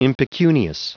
Prononciation du mot impecunious en anglais (fichier audio)
Prononciation du mot : impecunious